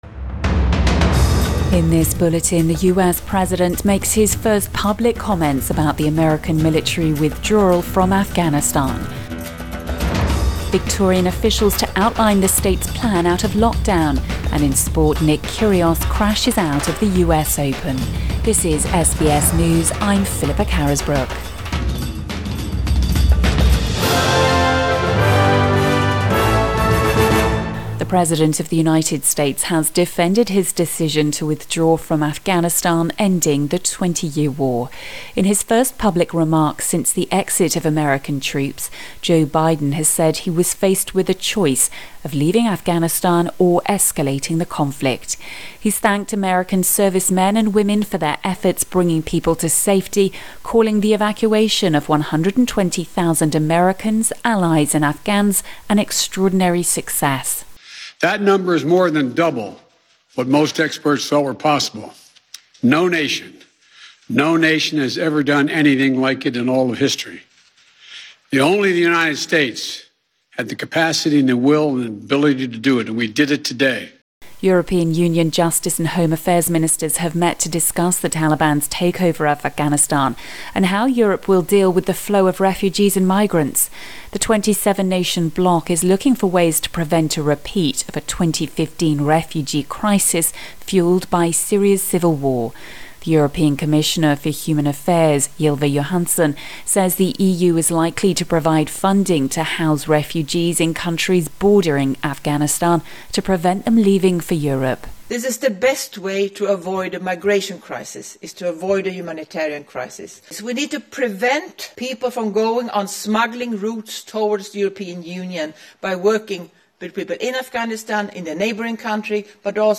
AM bulletin 1 September 2021